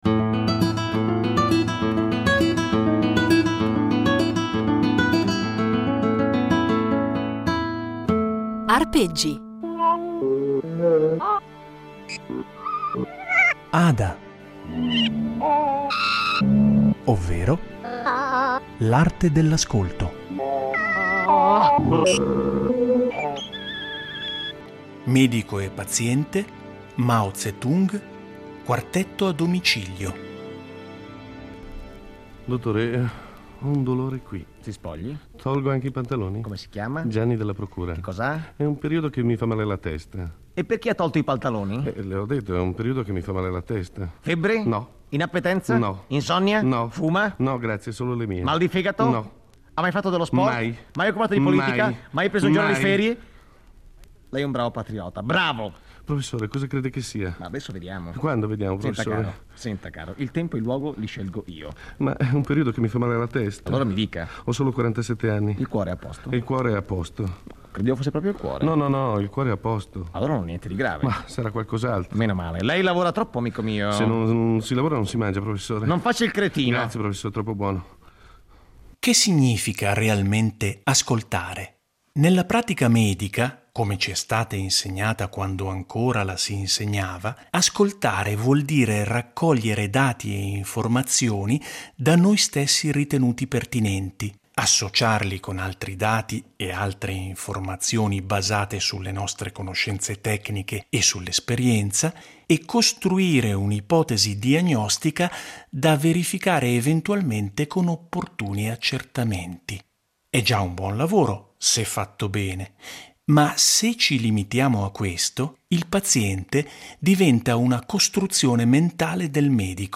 un patchwork fatto di musiche, suoni, rumori e letture per invitare gli ascoltatori di Rete Due ad aprire bene le orecchie, e a coltivare una sana curiosità